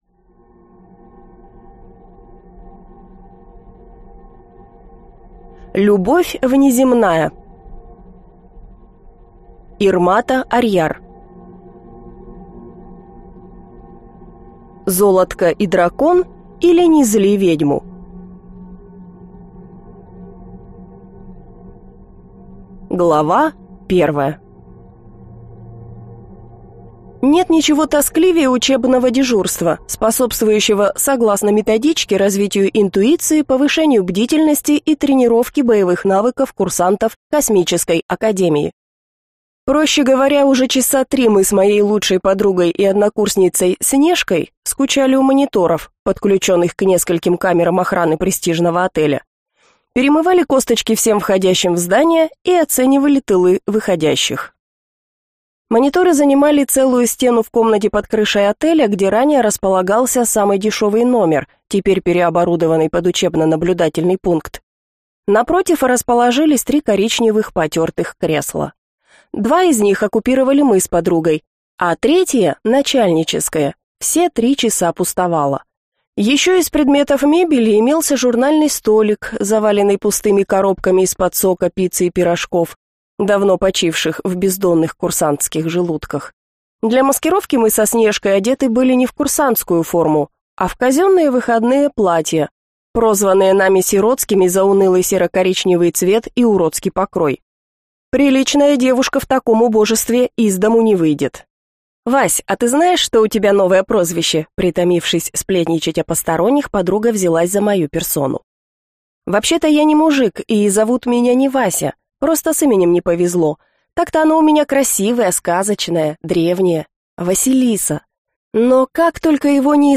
Аудиокнига Золотко и дракон, или Не зли ведьму | Библиотека аудиокниг